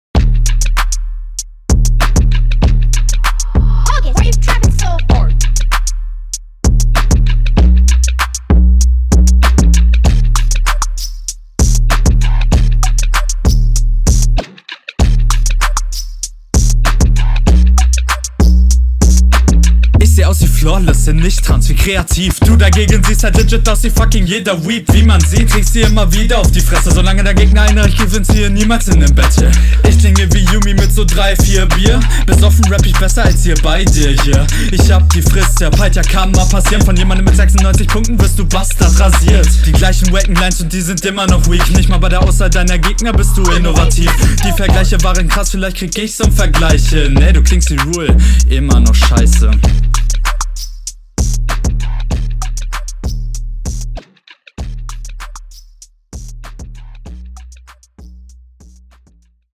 Ich find du stolperst auf den Beat echt bisschen mit deinen Shuffles